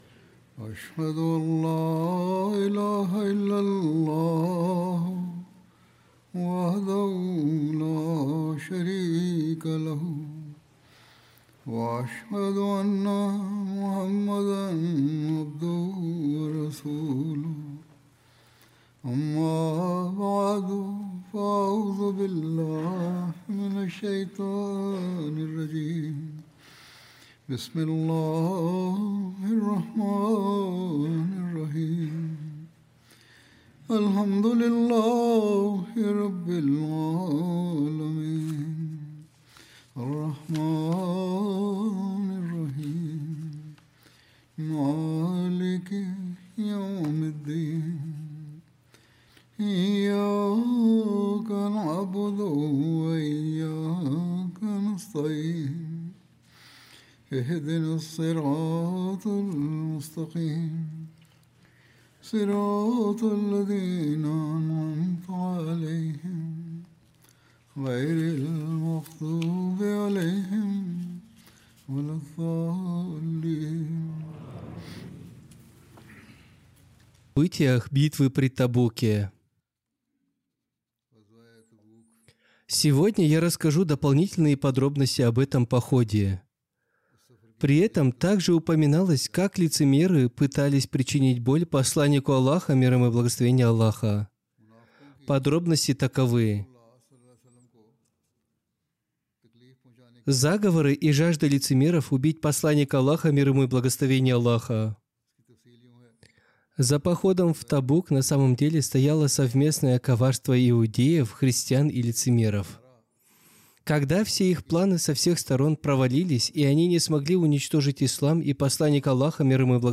Russian translation of Friday Sermon delivered by Khalifa-tul-Masih on November 21st, 2025 (audio)